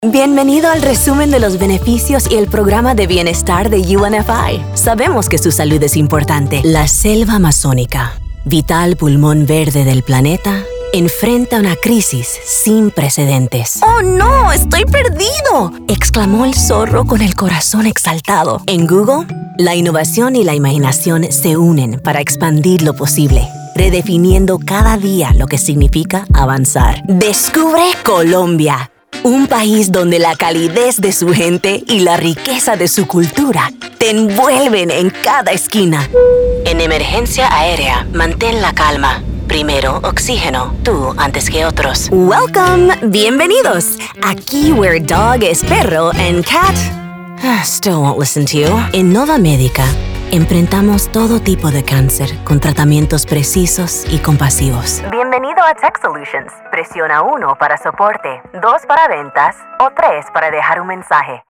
Female Voice Over, Dan Wachs Talent Agency.
Personal, Persuasive, Warm
Narration